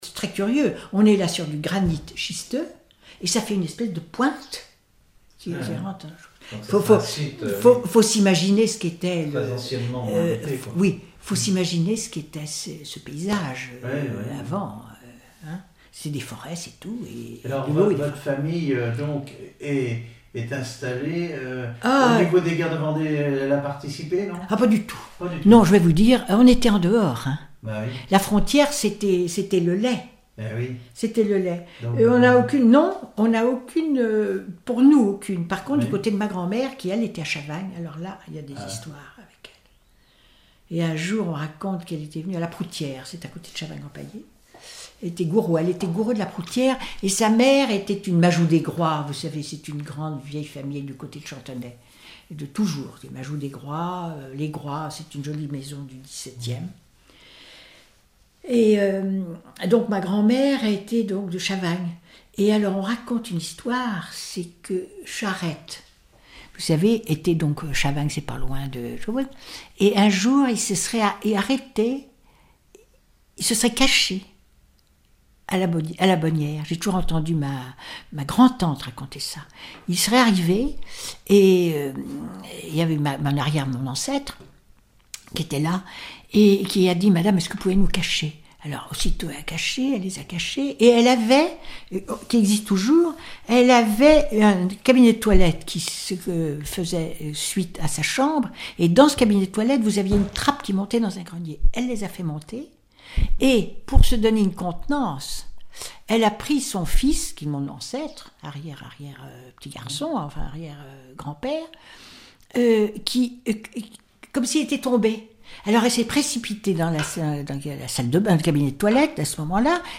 Enquête Arexcpo en Vendée-C.C. Deux Lays
Catégorie Témoignage